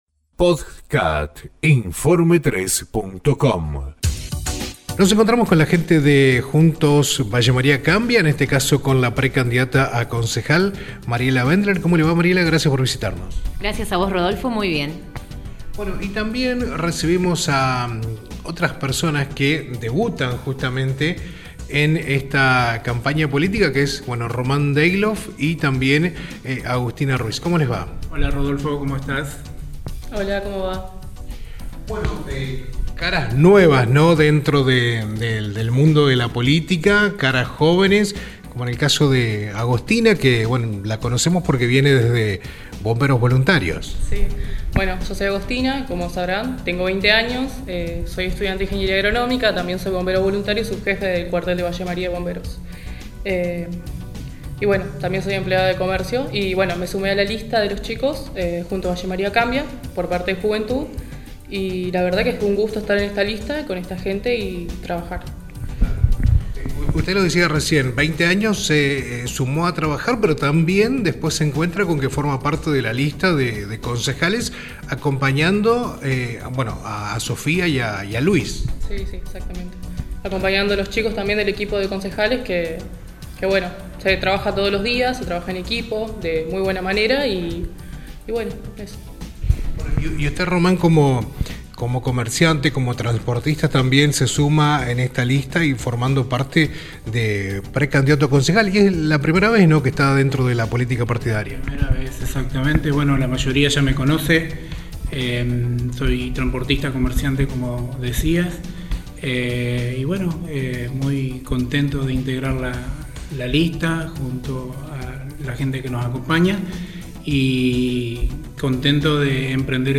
Elecciones 2023: Entrevista